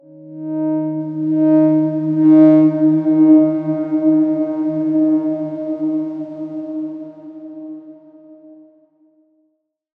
X_Darkswarm-D#3-pp.wav